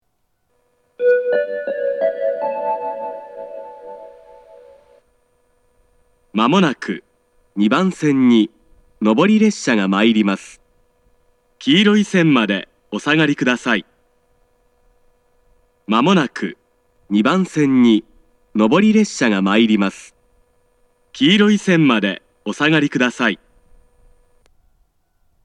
仙石型（男性）
接近放送
接近放送を録音開始する目安は踏切の音です。
Minami-Hashimoto-2Sekkin.mp3